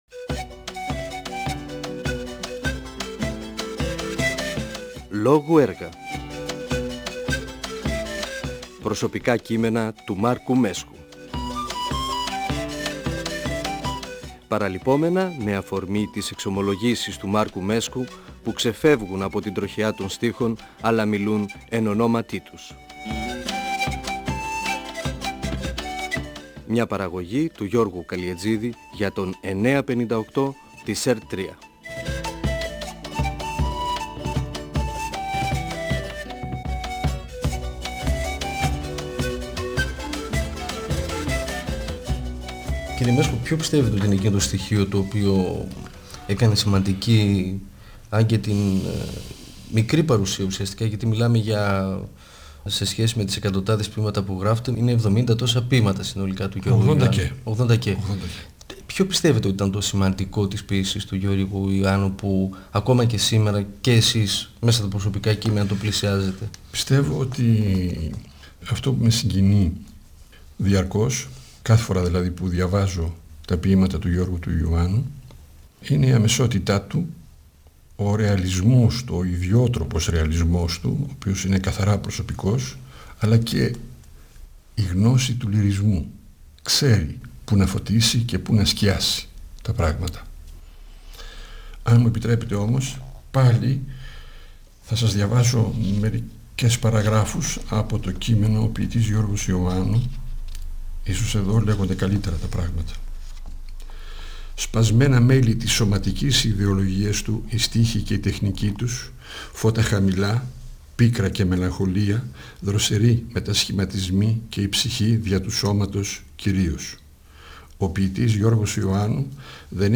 Ο ποιητής και δοκιμιογράφος Μάρκος Μέσκος (1935-2019) μιλά για το ποιητικό έργο του Γιώργου Ιωάννου (εκπομπή 2η ) και για τα ιδιαίτερα χαρακτηριστικά τής ποίησής του, για την αμεσότητα των γραπτών του και για το σύνολο της προσφοράς του.
Αφορμή για τη συζήτηση με τον Μάρκο Μέσκο στάθηκε το βιβλίο του «Προσωπικά κείμενα» (εκδ. Νεφέλη, 2000).ΦΩΝΕΣ ΑΡΧΕΙΟΥ του 958fm της ΕΡΤ3.